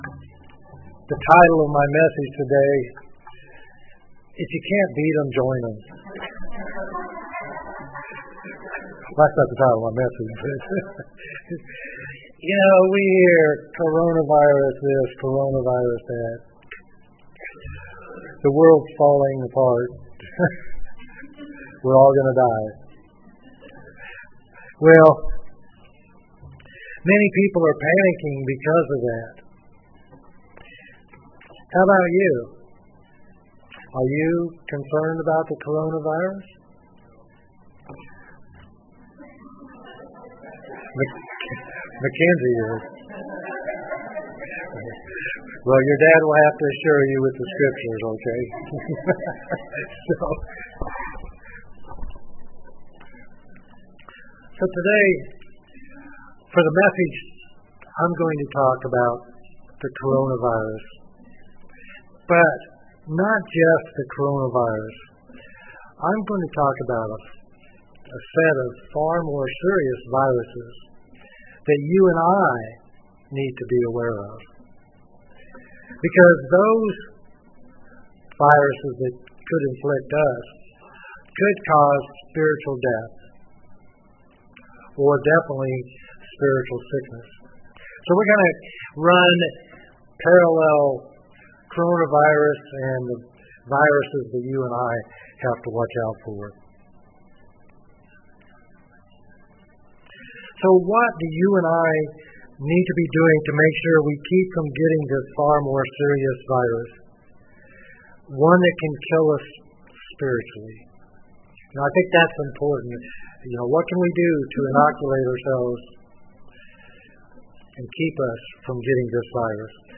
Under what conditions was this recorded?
Given in Laurel, MS New Orleans, LA